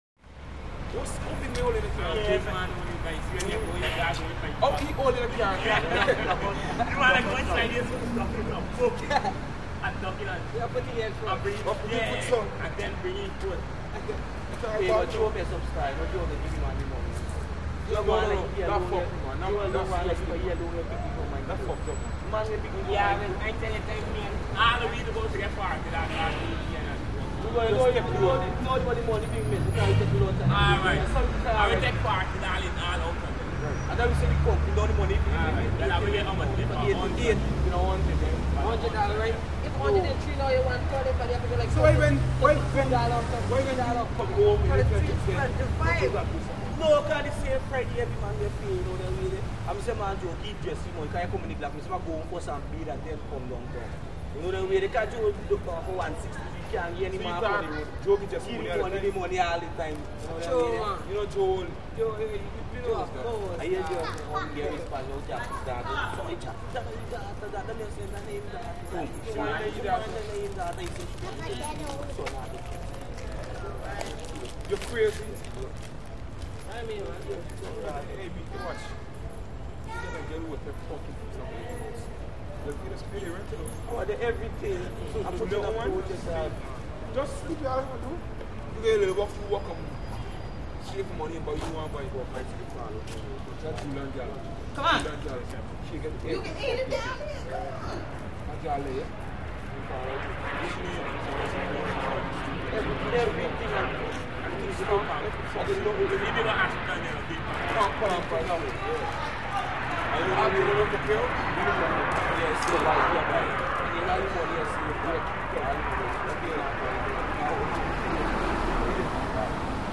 Jamaican Males Street Walla Medium Perspective. More Active, Excited Walla And Wider Perspective Than Track 1001-12. Traffic Roar Distant With A Few Medium Bys. Some Female And Child Walla Medium.